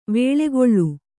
♪ vēḷegoḷḷu